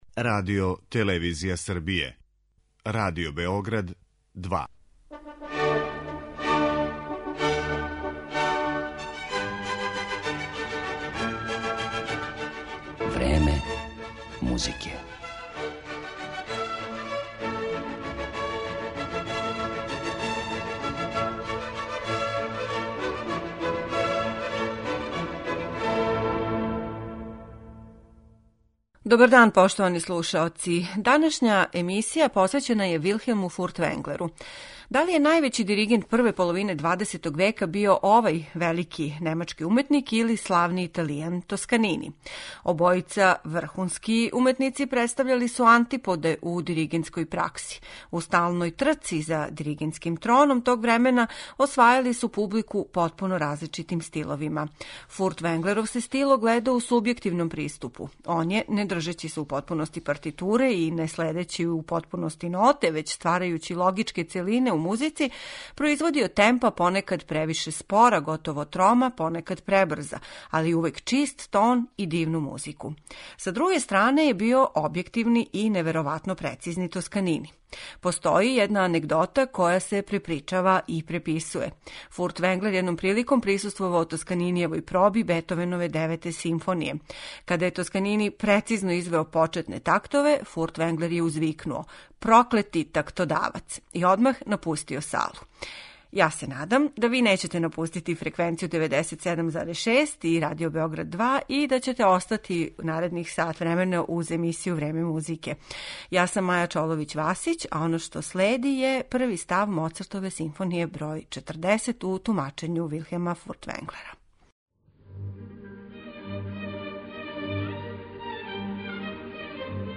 Фрагментима из композиција Моцарта, Бетовена, Брамса, Вагнера и других представићемо немачког диригената Вилхелма Фуртвенглера.
(сада већ архивских) снимака